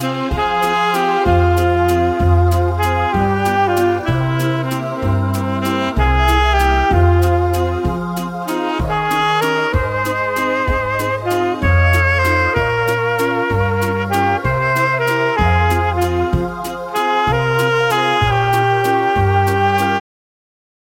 230 Простых мелодий для саксофониста